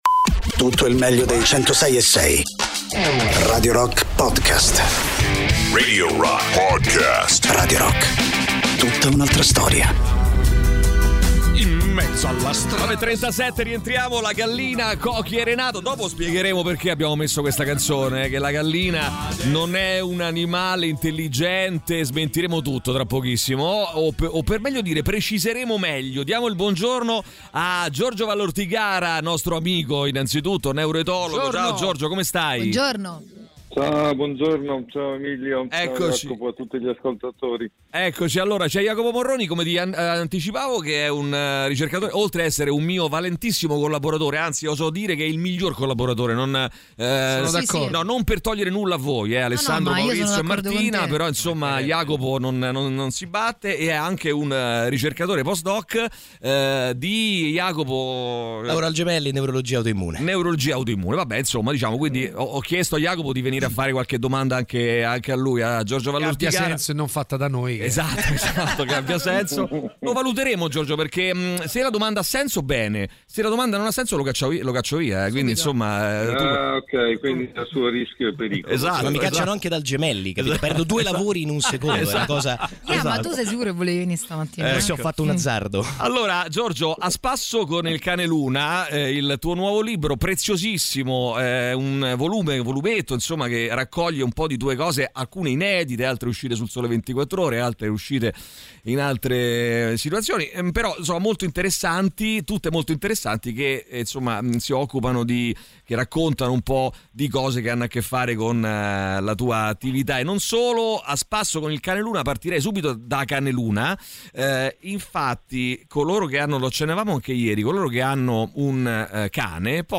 Interviste: Giorgio Vallortigara (08-04-25)